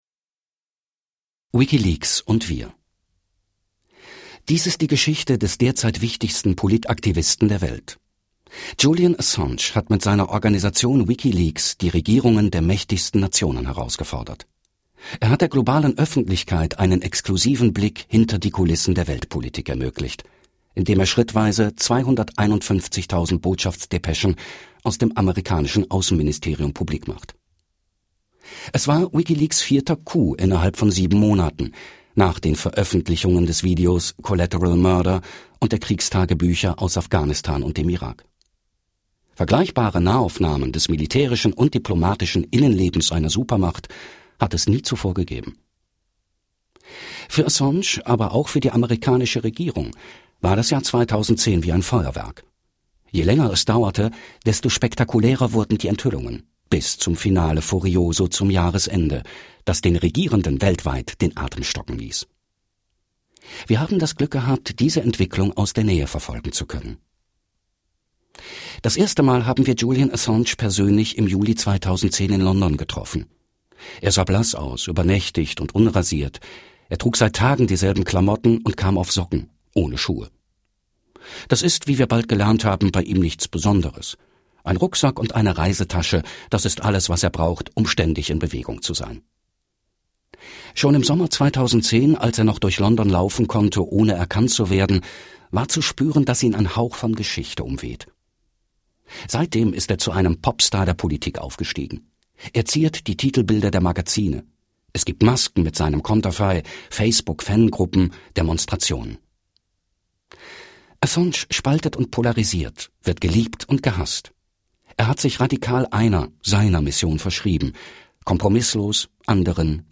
Reihe/Serie DAV Lesung